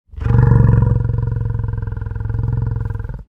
Звуки буйвола
Тихий вздох